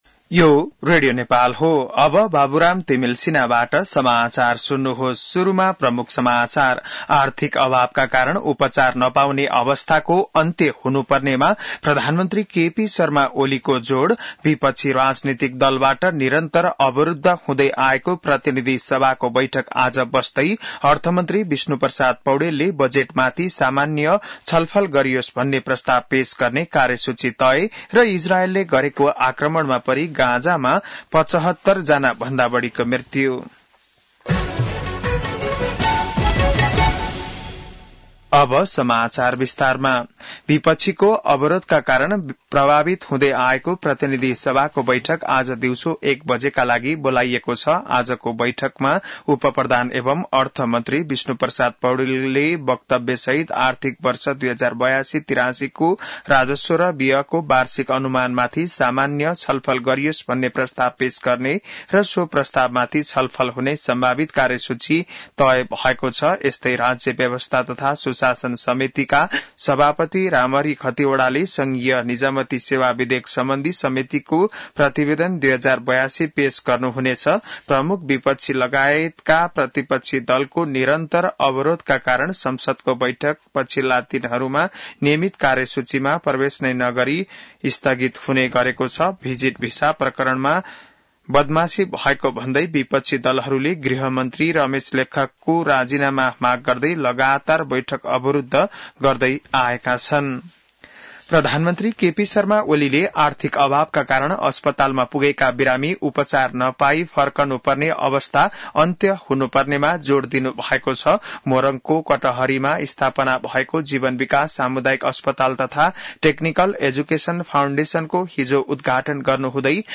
An online outlet of Nepal's national radio broadcaster
बिहान ९ बजेको नेपाली समाचार : २५ जेठ , २०८२